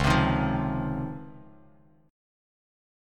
C#M9 chord